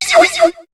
Cri de Blizzi dans Pokémon HOME.